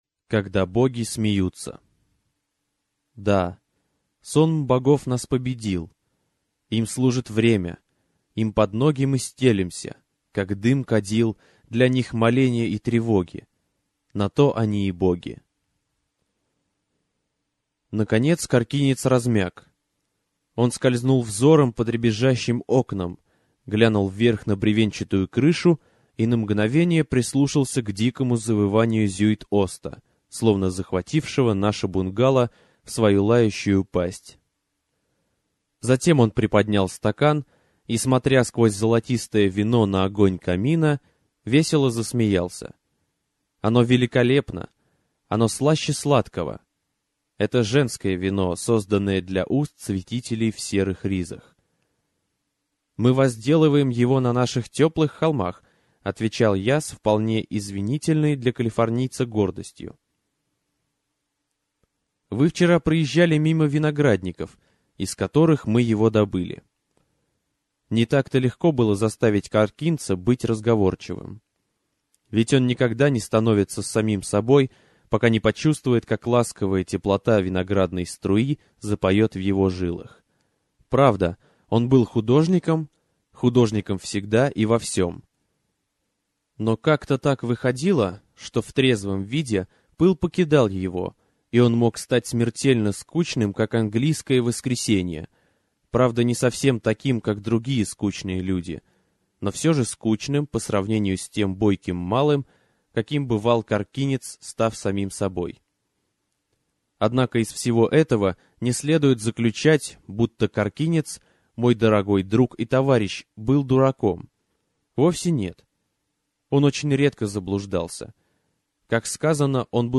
Аудиокнига Когда боги смеются. Рожденная в ночи | Библиотека аудиокниг